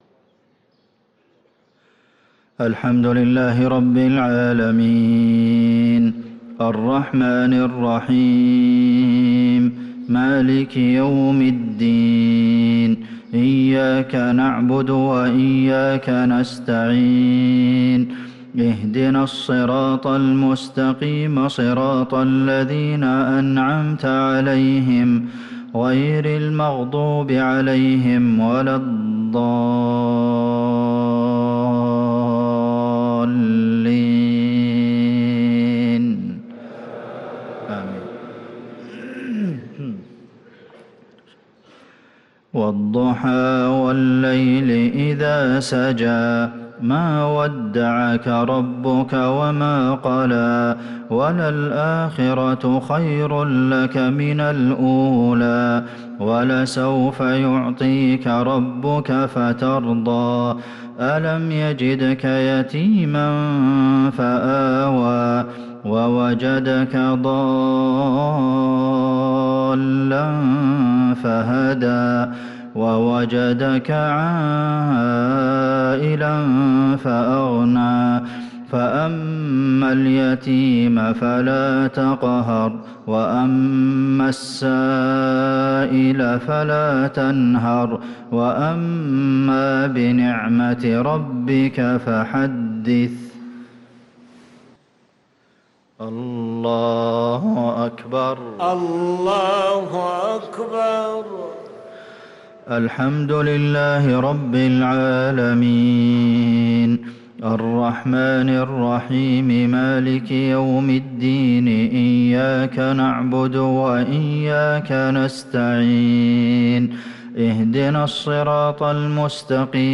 صلاة المغرب للقارئ عبدالمحسن القاسم 3 رجب 1445 هـ
تِلَاوَات الْحَرَمَيْن .